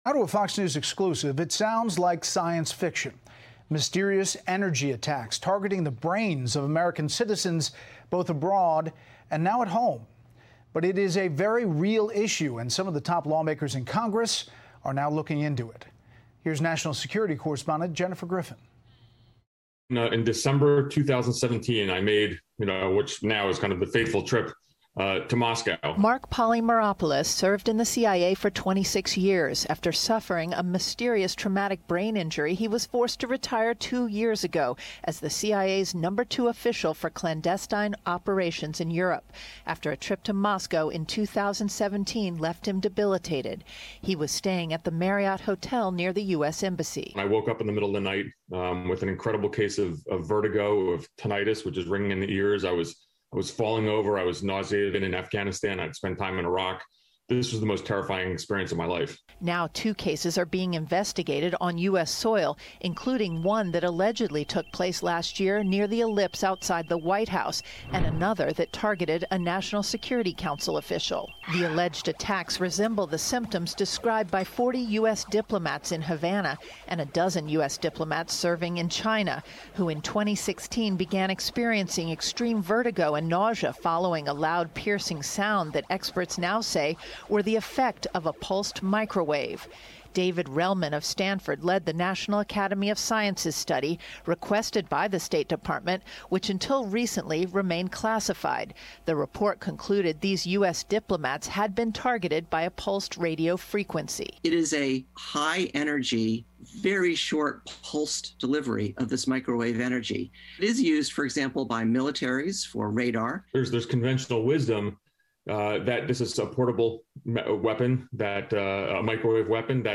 FOX News national security correspondent Jennifer Griffin has the details on 'Special Report'